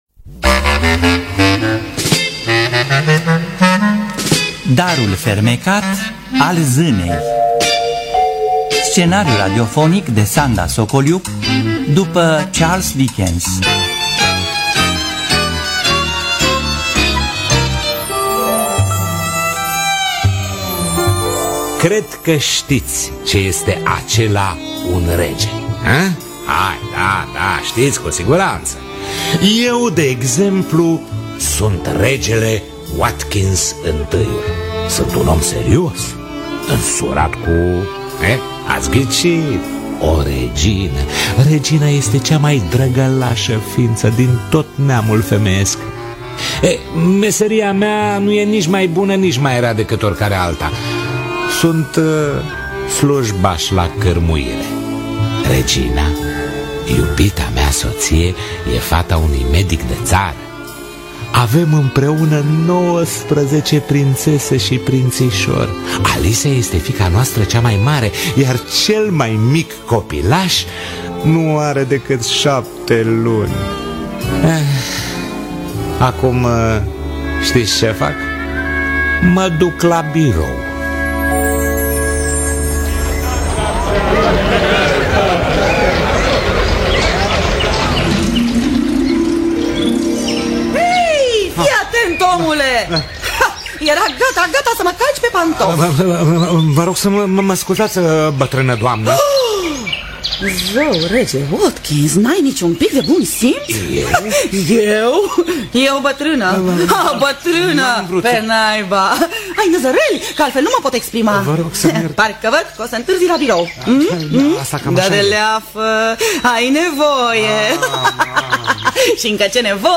„Darul fermecat al zânei” de Charles Dickens – Teatru Radiofonic Online